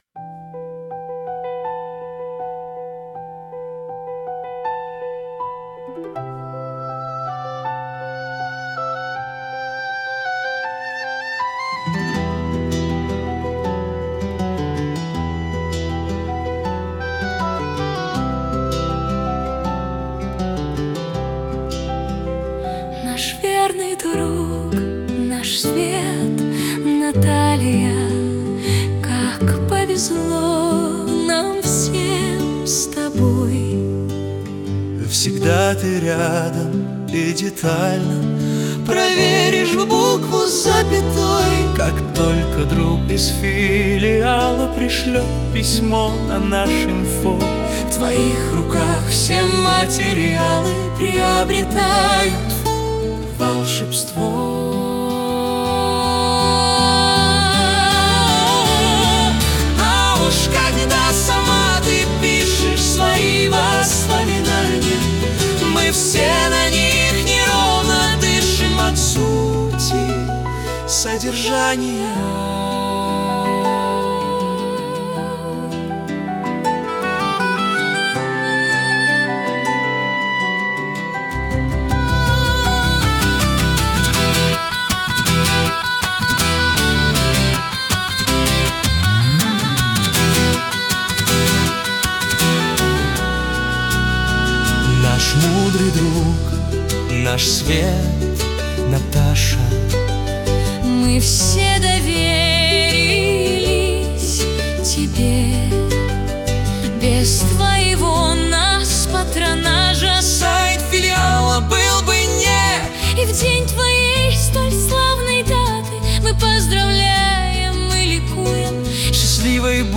Опера: